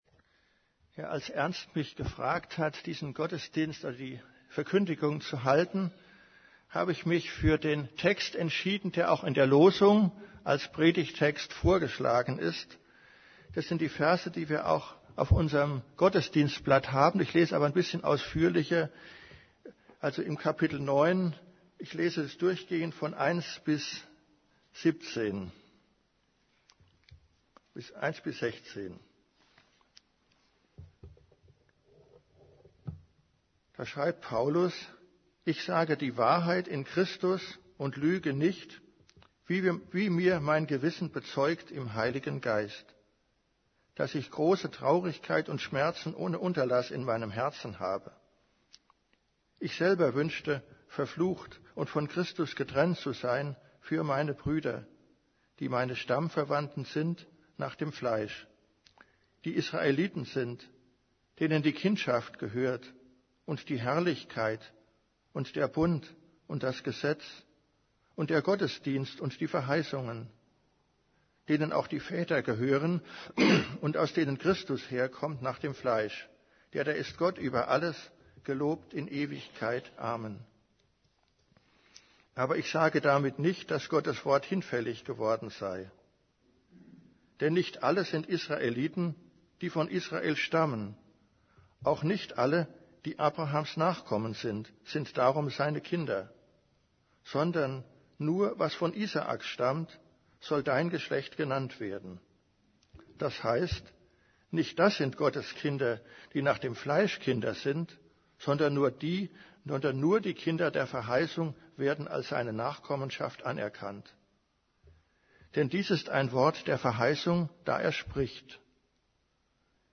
> Übersicht Predigten Gottes freies Geschenk und unsere Wahl Predigt vom 08.